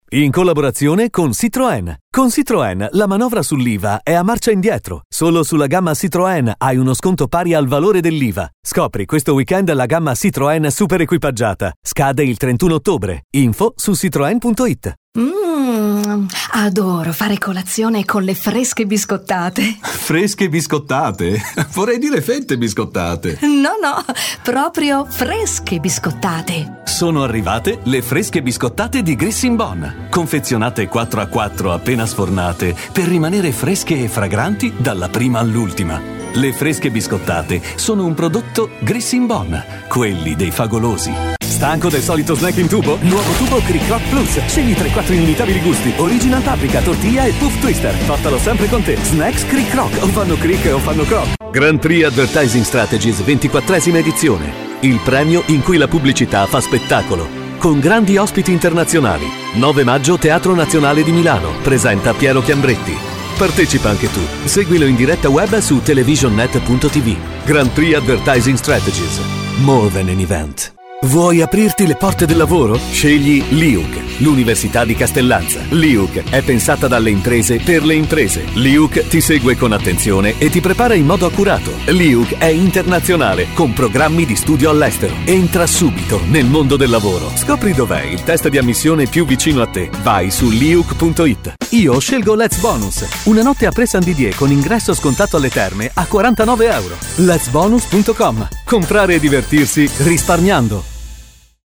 Sprecher italienisch.
Sprechprobe: Werbung (Muttersprache):
Italian voice over talent.